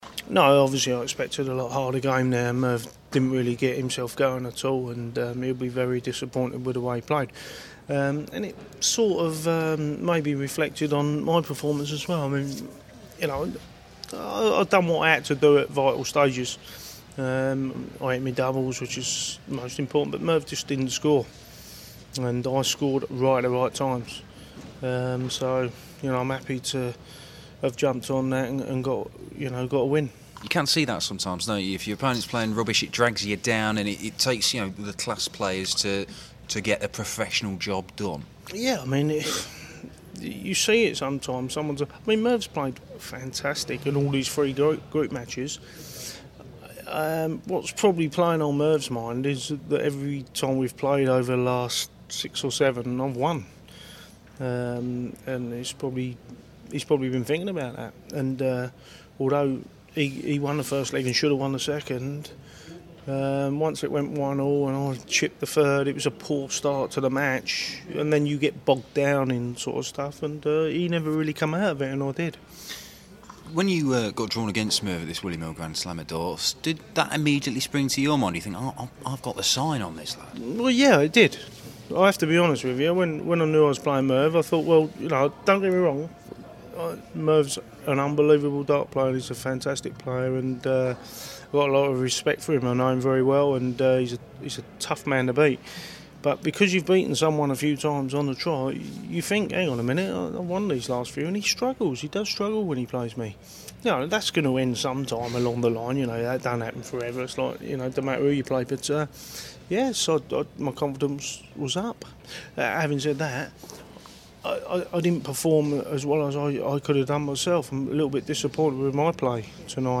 William Hill GSOD - Painter Interview (Last 16)